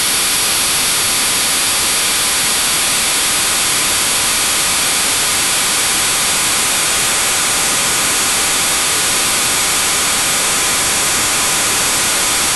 DVB-t.mp3